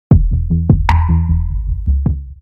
• Качество: 320, Stereo
короткие
цикличные